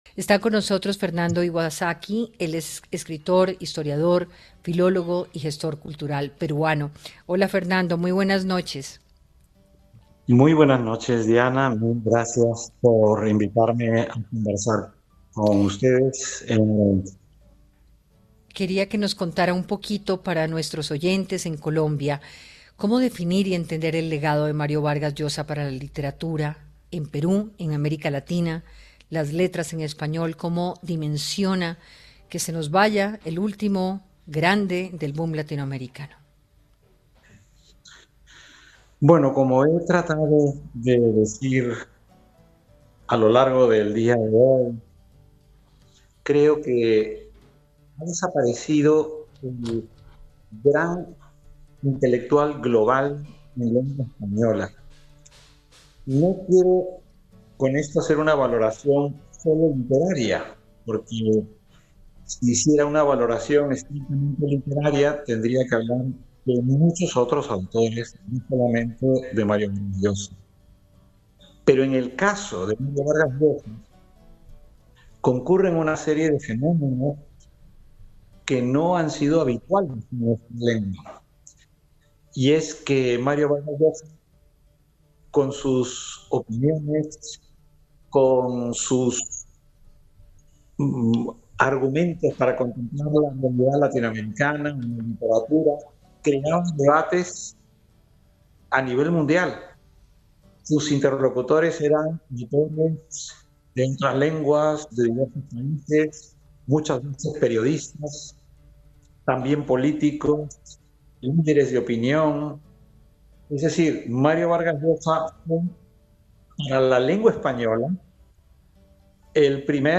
En diálogo con Hora20 de Caracol Radio, el escritor y filólogo peruano, Fernando Iwasaki, planteó que con la muerte de Mario Vargas Llosa desaparece el gran intelectual global de la lengua española, “él con sus opiniones, con sus argumentos para contemplar la realidad, tenía impacto a nivel global, sus interlocutores eran de otras lenguas, periodistas, políticos, Vargas Llosa para la lengua española era el primer y único intelectual global”.